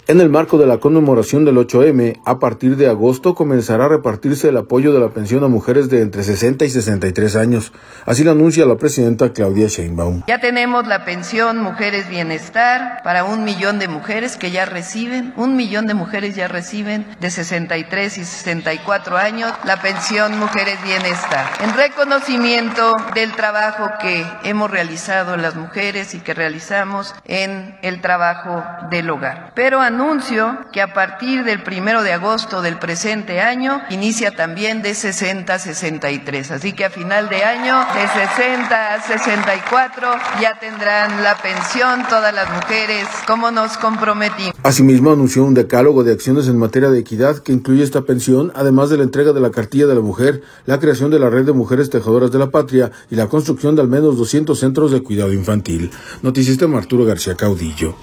En el marco de la conmemoración del 8M, a partir de agosto comenzará a repartirse el apoyo de la pensión a mujeres de entre 60 y 63 años, así lo anuncia la presidenta Claudia Sheinbaum.